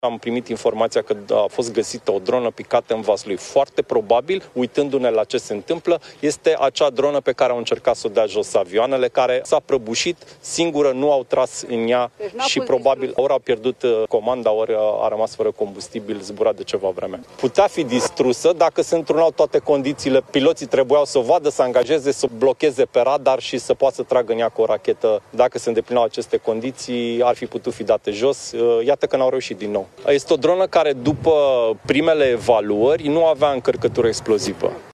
O dronă a căzut marți, 25 noiembrie, înainte de ora prânzului, în localitatea Puiești, județul Vaslui, a declarat ministrul Apărării, Ionuț Moșteanu, aflat la la baza aeriană „Mihail Kogălniceanu” din județul Constanța.
25nov-12-Mosteanu-–-Despre-drona-din-Vaslui-.mp3